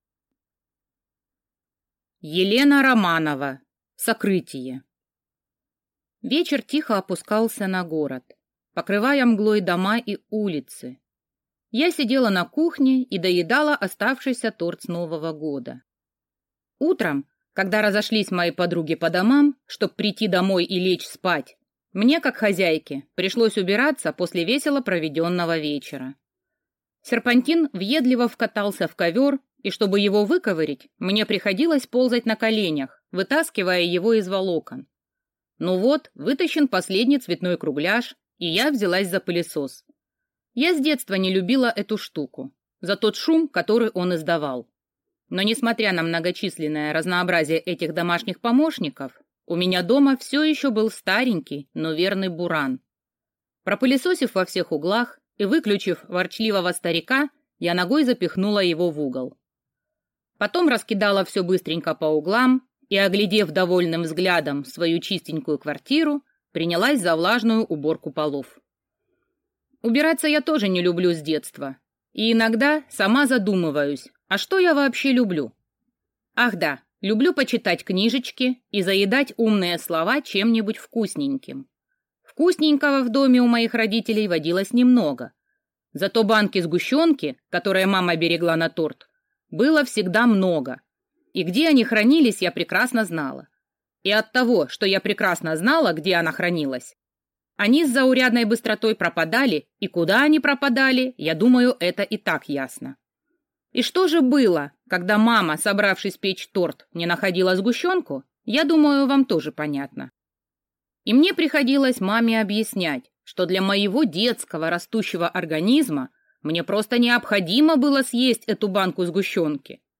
Аудиокнига Сокрытие | Библиотека аудиокниг